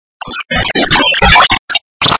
Toilet flush
Sound of flushing toilet. Watertank filling up afterwards.
Toiletflush.mp3